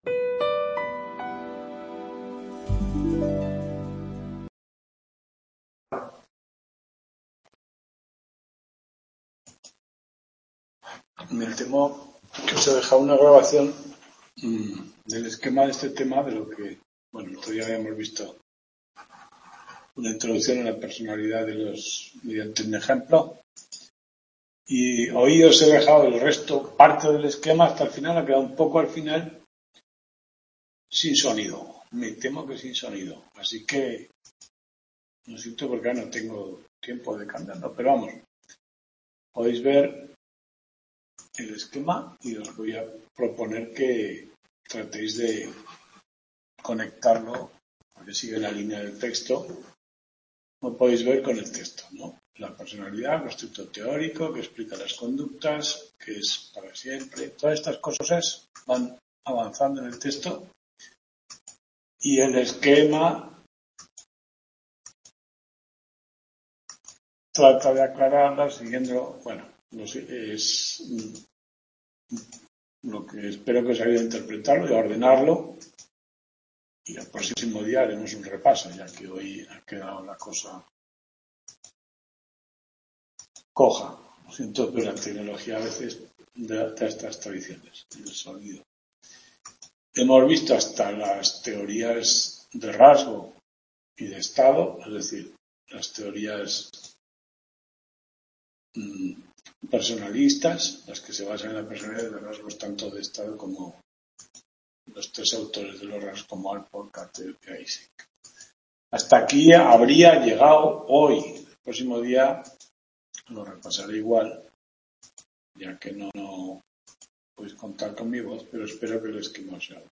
Tutoría 2
Video Clase